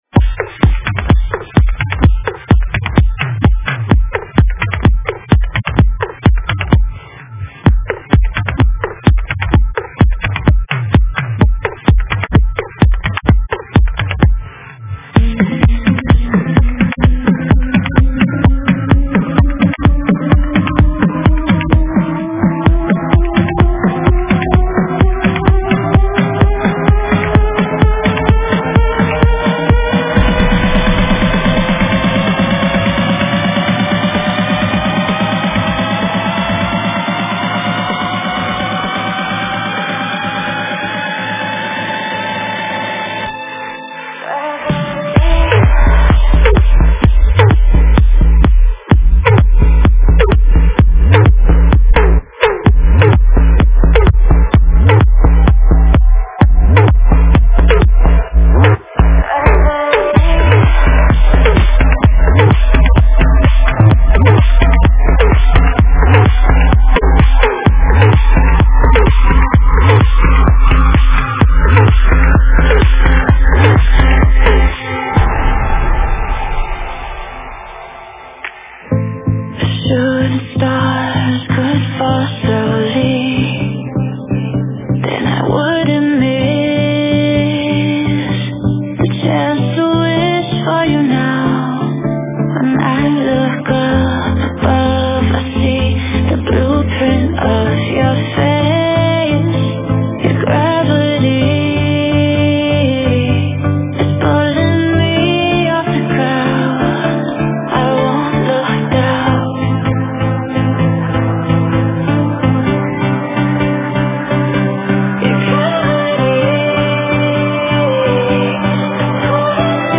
Стиль: Trance / Progressive Trance / Vocal Trance